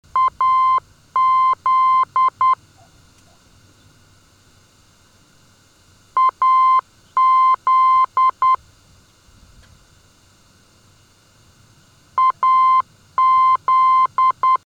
AZ - Kalamazoo heard on 371 kHz: (230 kb)